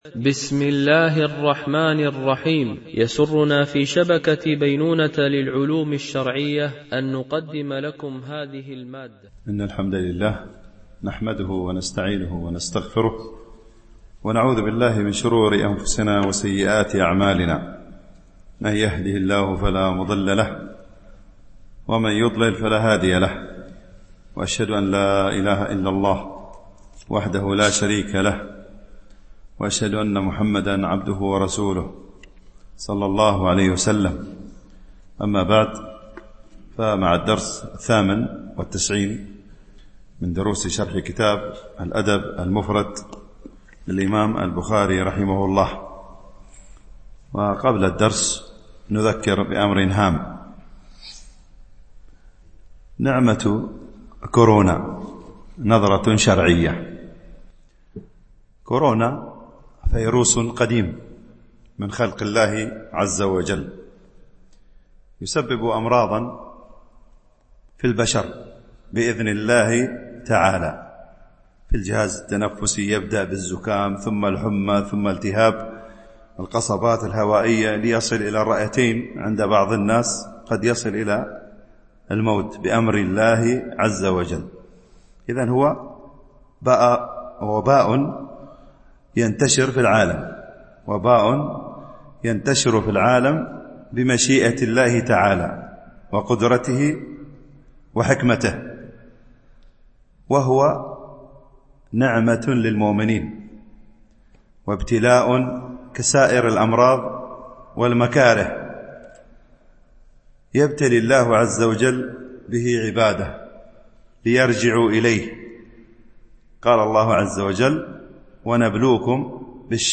شرح الأدب المفرد للبخاري ـ الدرس 98 ( الحديث 700 – 704 )
التنسيق: MP3 Mono 22kHz 32Kbps (CBR)